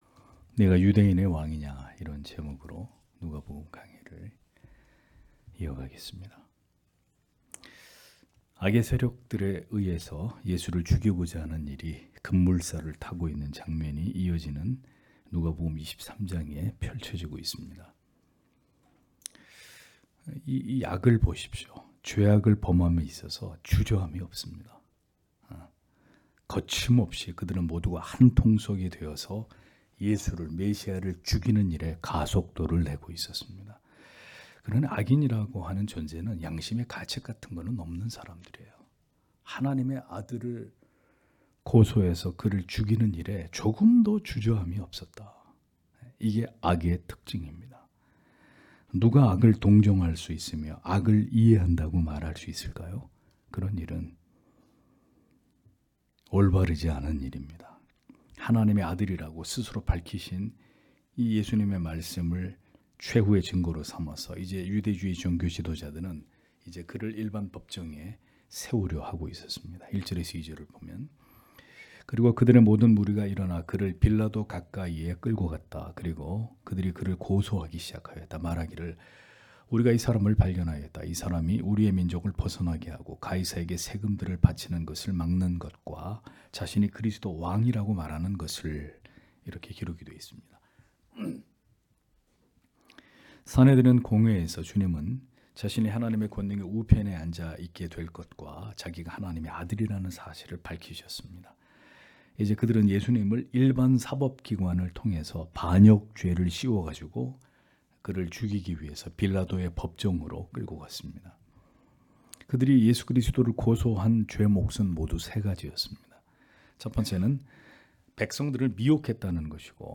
금요기도회 - [누가복음 강해 175] '네가 유대인의 왕이냐' (눅 23장 1- 12절)